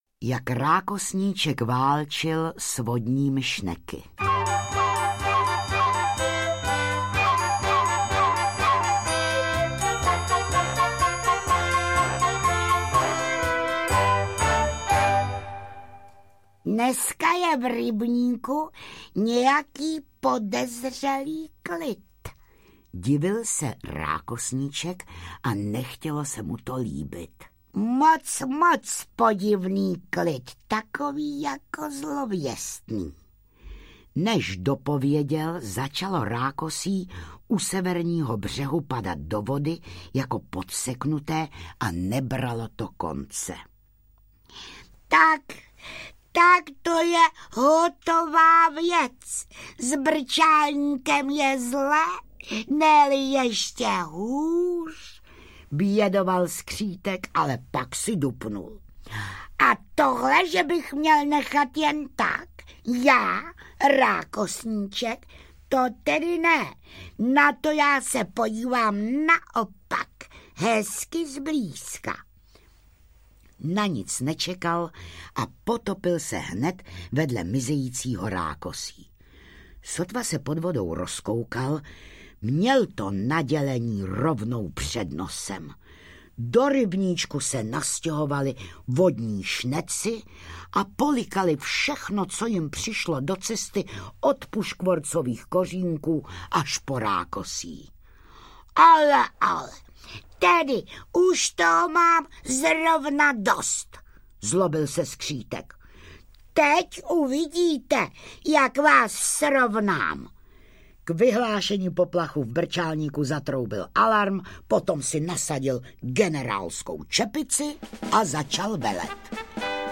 Rákosníček (komplet) audiokniha
Ukázka z knihy
Audioknižní komplet nabízí všechny oblíbené příběhy skřítka Rákosníčka v neopakovatelné interpretaci Jiřiny Bohdalové s originální hudbou Václava Zahradníka a ilustrací Zdeňka Smetany, výtvarníka a animátora stejnojmenného večerníčkovského seriálu České televize.
• InterpretJiřina Bohdalová